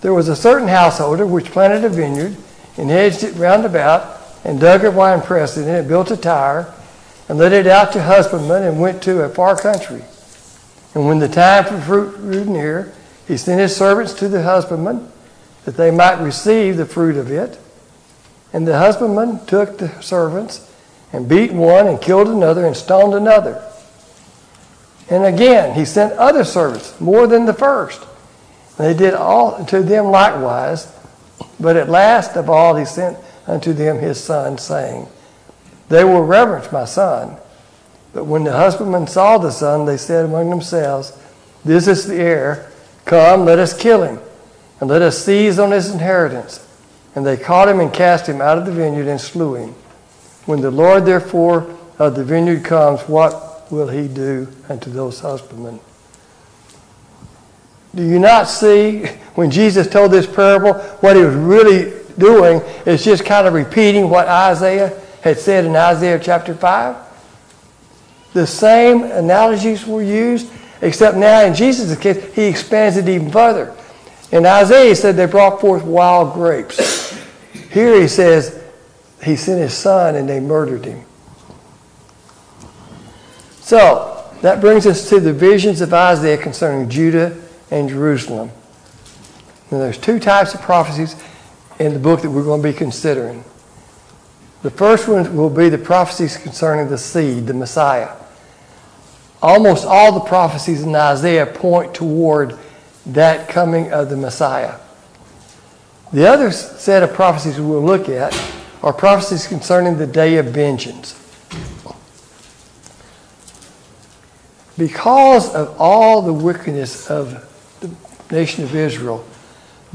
God's Scheme of Redemption Service Type: Sunday Morning Bible Class « Study of Paul’s Minor Epistles